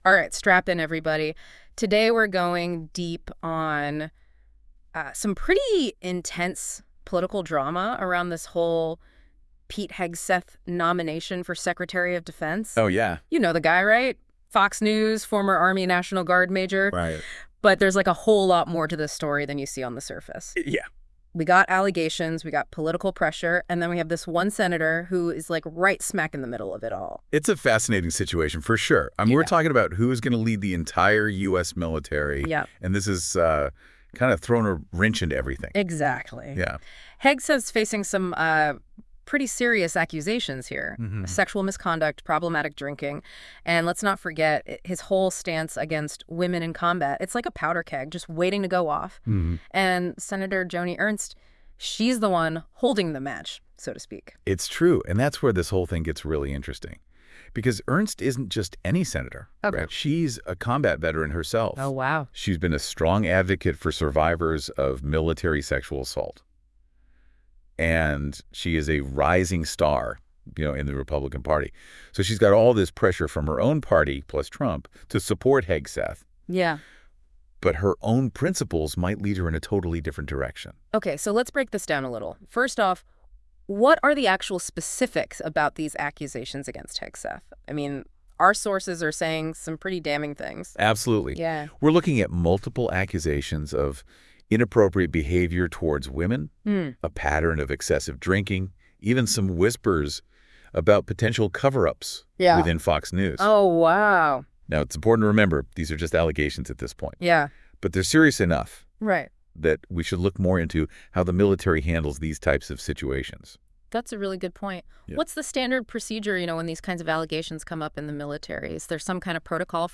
Listen to a convo on the while situation – using Politico article as basis.